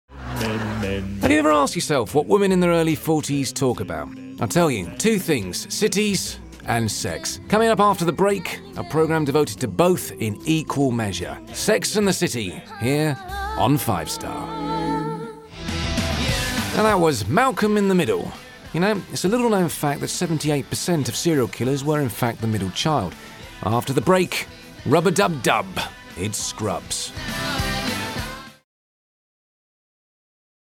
Warm, voll und ohne regionalen Akzent. Er arbeitet von seinem Heimstudio aus und verwendet nur die beste Ausrüstung.
Ich verwende ein Sennheiser MKH-416-Mikrofon, Audacity, Focusrite Scarlett Solo und habe ein komplett ausgestattetes Aufnahmestudio.